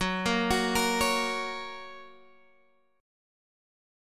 Gb Chord
Listen to Gb strummed